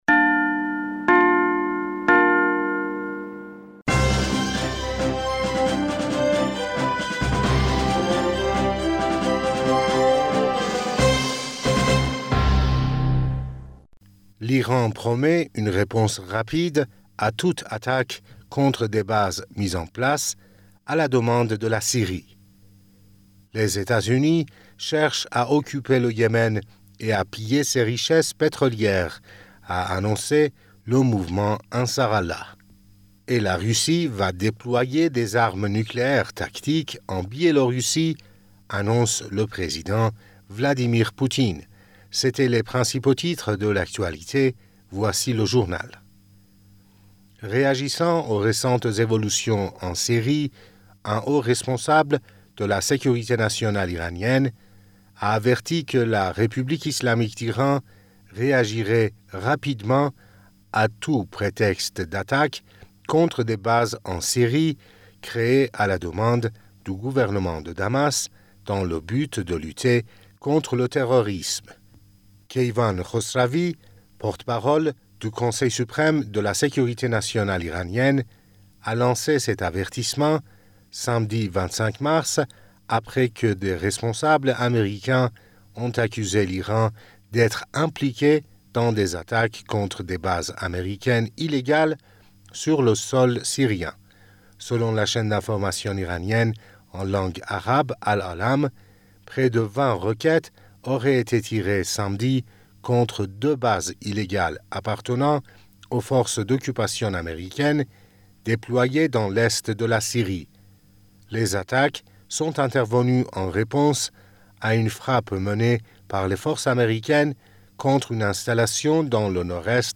Bulletin d'information du 26 Mars